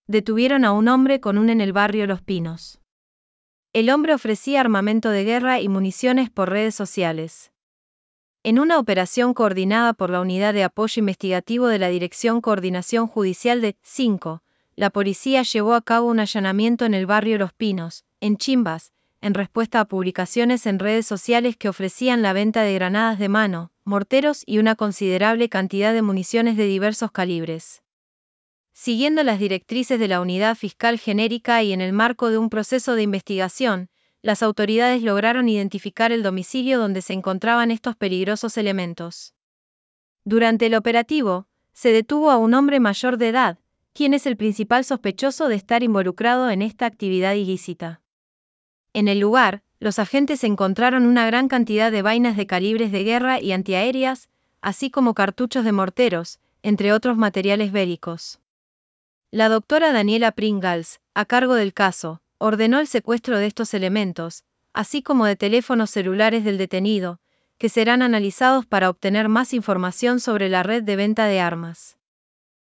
Text_to_Speech-3.wav